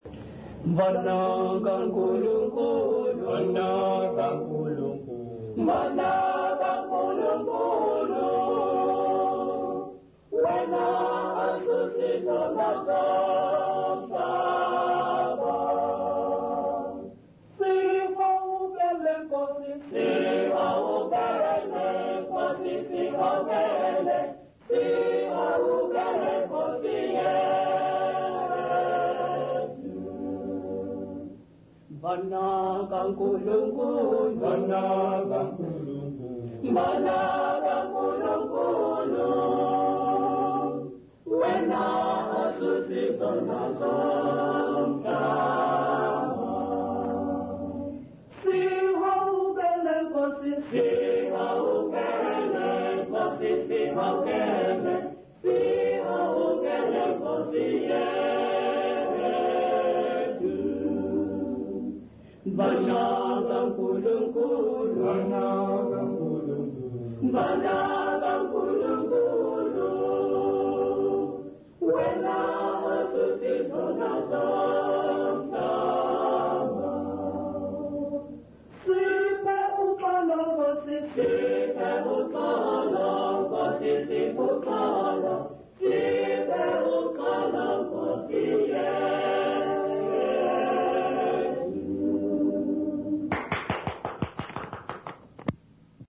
Intshanga church music workshop participants
Sacred music South Africa
Folk music South Africa
Hymns, Zulu South Africa
field recordings
Unaccompanied church hymn.